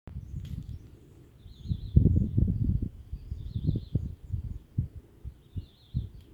Bico-de-pimenta-chaquenho (Saltatricula multicolor)
Detalhada localização: Jardín Botánico Provincial
Condição: Selvagem
Certeza: Gravado Vocal